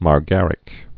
(mär-gărĭk)